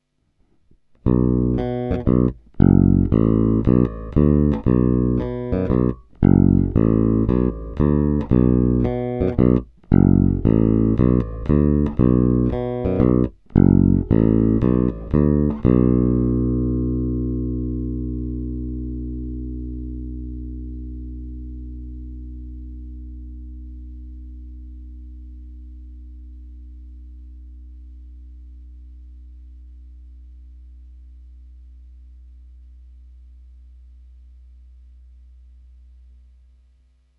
Zvukově je to opravdu typický Jazz Bass se vším všudy.
Není-li uvedeno jinak, následující nahrávky jsou provedeny rovnou do zvukové karty, jen normalizovány, jinak ponechány bez úprav.
Oba snímače